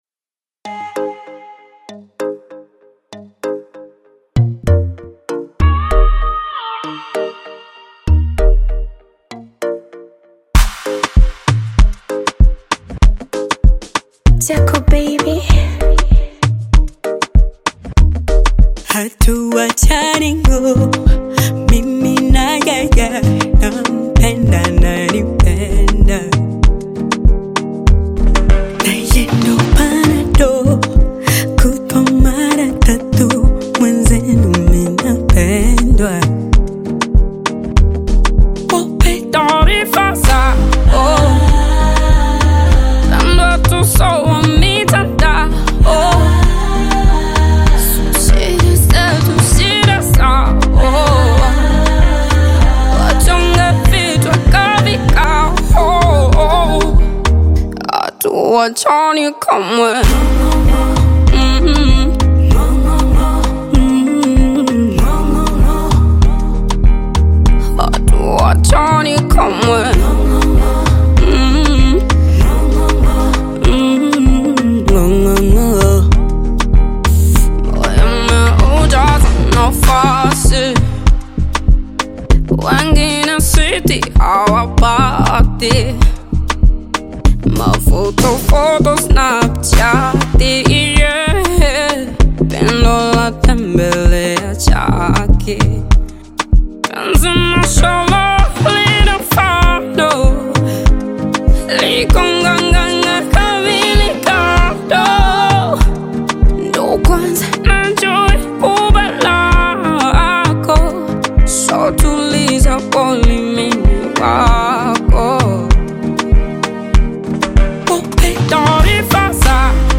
This catchy new song